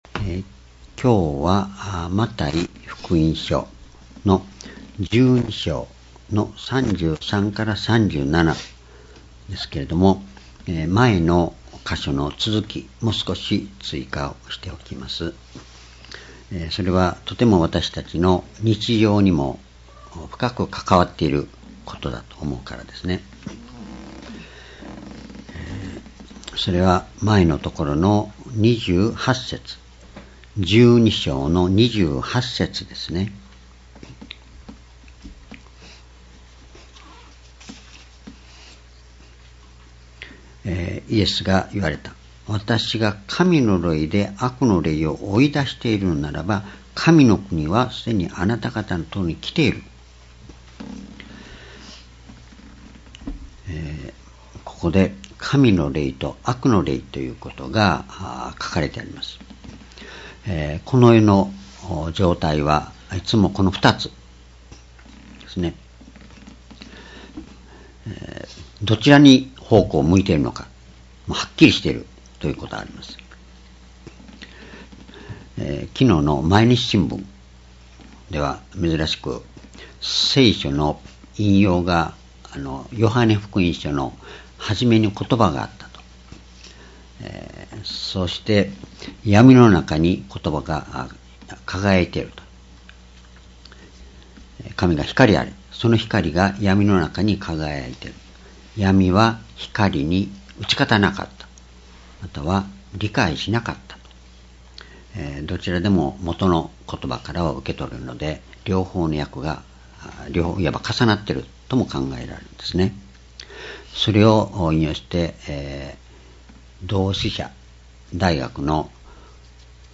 主日礼拝日時 2017年10月22日 主日 聖書講話箇所 「悪霊を追い出す(その２)・ことばの重要性」 マタイ福音書12章33節-37節 ※視聴できない場合は をクリックしてください。